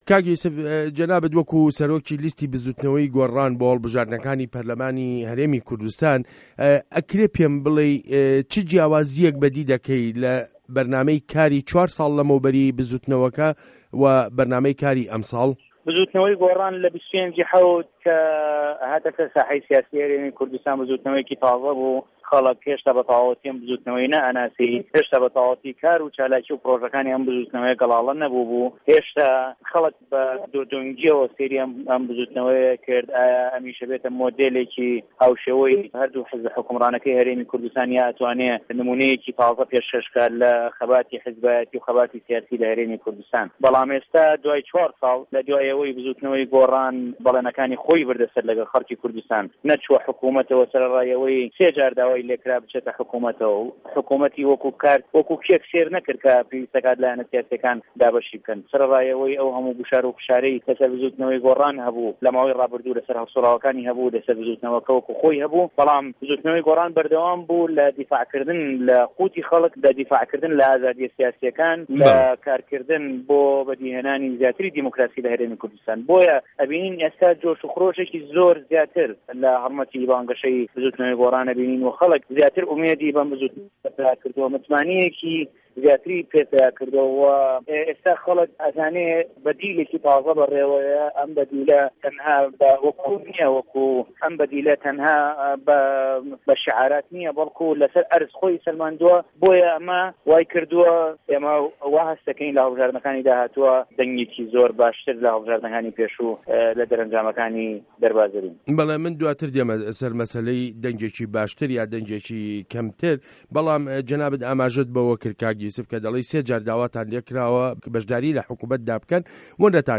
وتووێژ له‌گه‌ڵ یوسف موحه‌مه‌د